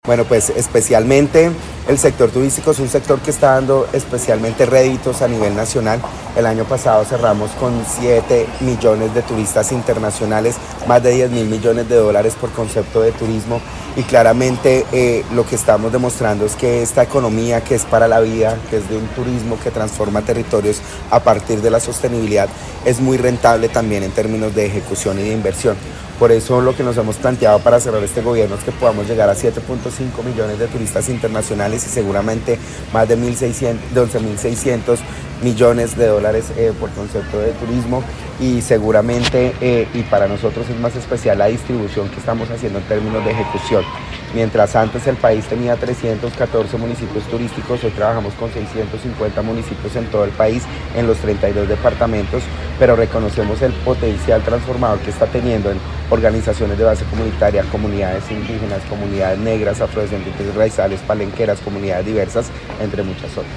Viceministro de Turismo
En el marco del Encuentro Nacional de Autoridades Regionales de Turismo que se llevó a cabo en el Quindío, el viceministro de Turismo, John Alexander Ramos Calderón dio a conocer el impacto económico que genera el sector para el país.